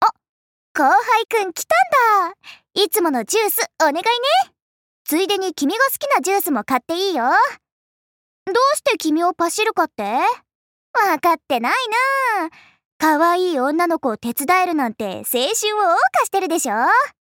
白石奈奈普通登录语音.mp3